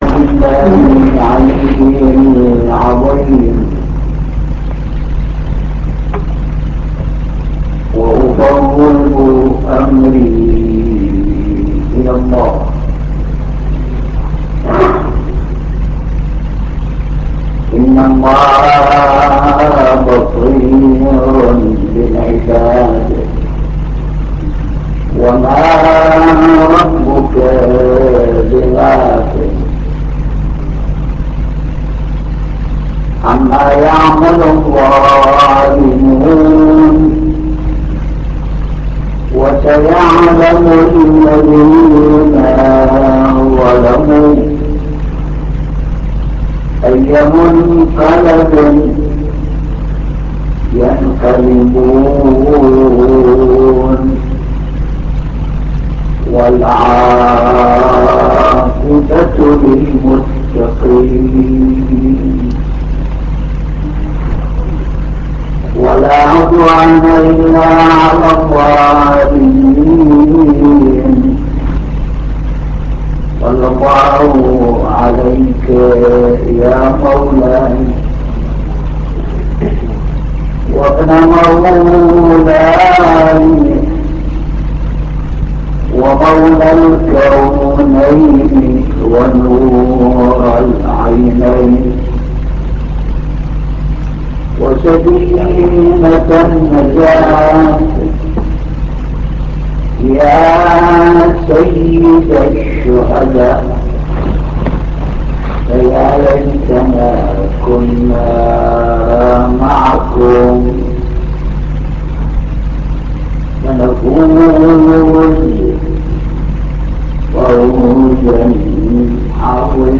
مجلس حسيني